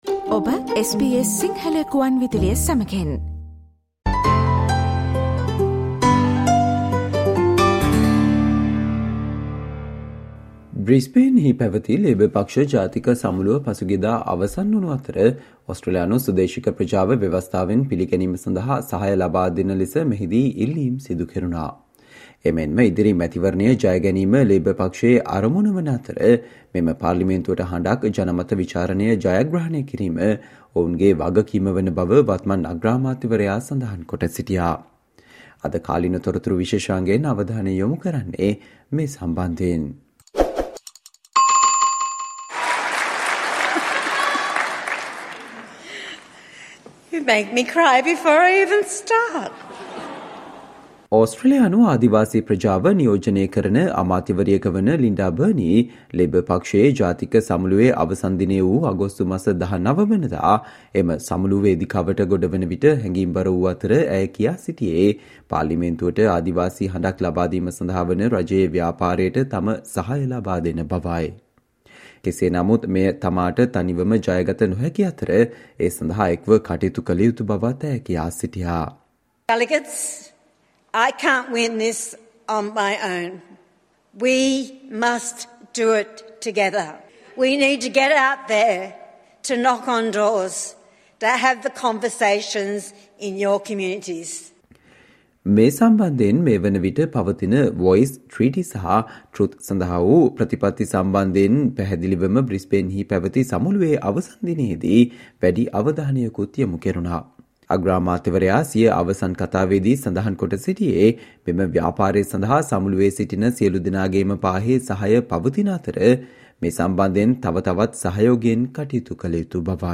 Listen to the SBS Sinhala radio digital explainer on The Prime Minister told members it's their responsibility to win the referendum, as Labor looks to win the next election.